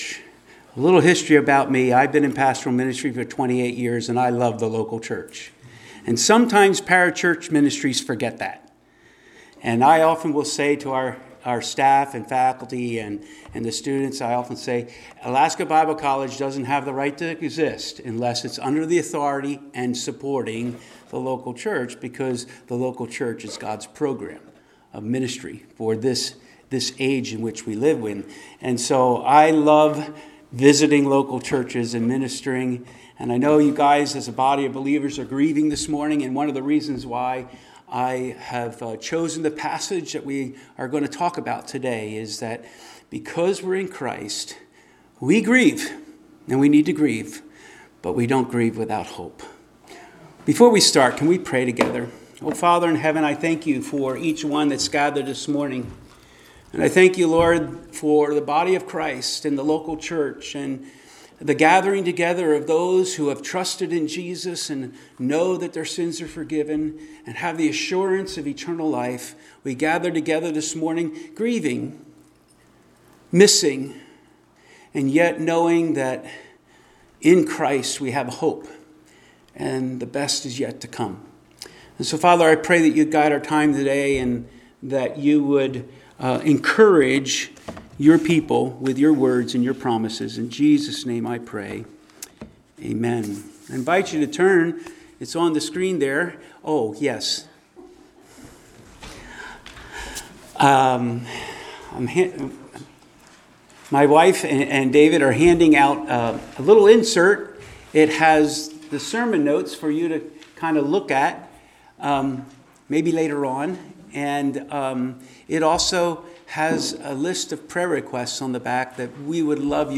Service Type: Sunday Morning Worship Topics: How Christ returns , Where Christ Returns , Who Christ returns for , Why Christ will return « “Faith” “At Wits End” »